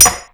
Shield3.wav